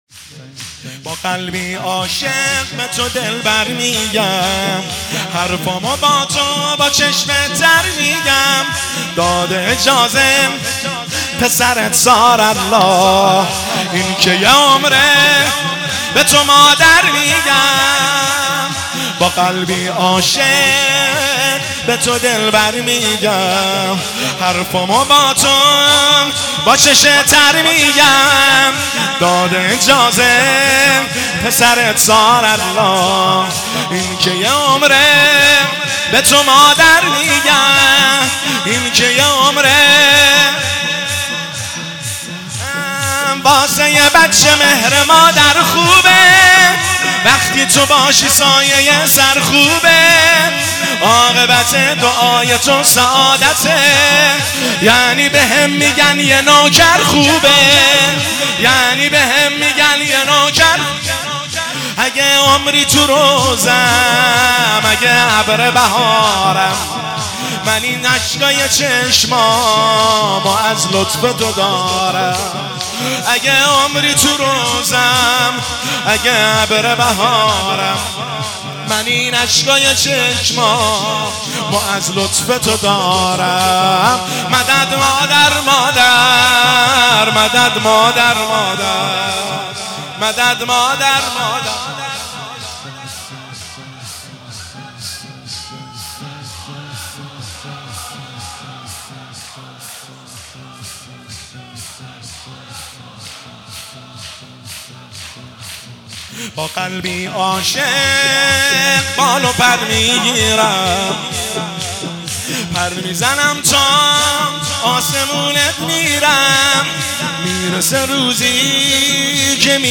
مداحی شور
فاطمیه دوم 1403